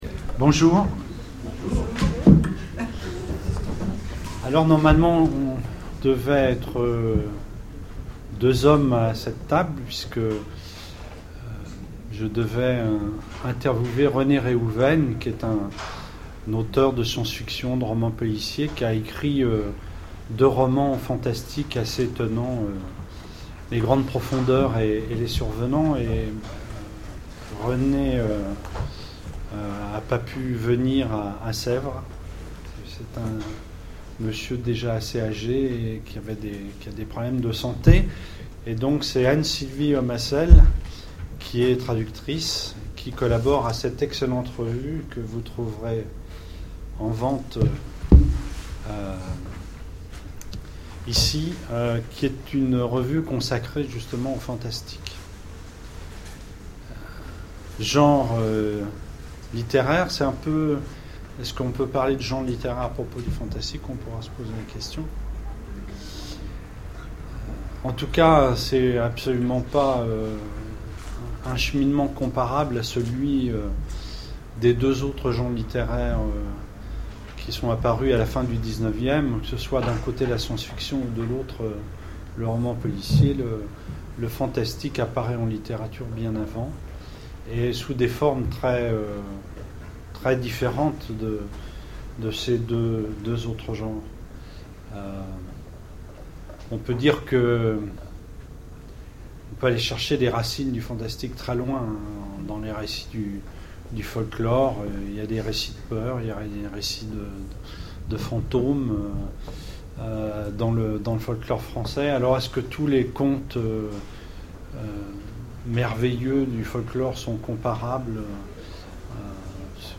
Rencontres de l'Imaginaire de Sèvres 2011 : Conférence sur le Fantastique
Conférence